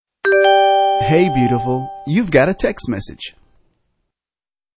Ringtones Category: Funny